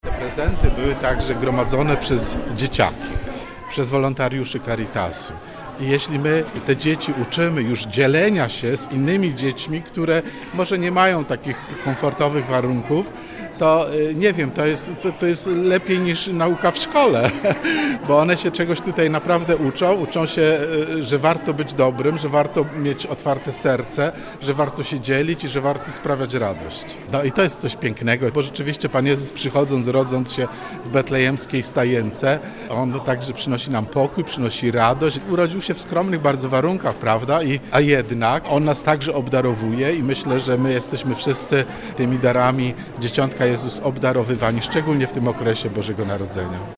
– mówił podczas wręczania paczek metropolita, ks. Abp Józef Kupny.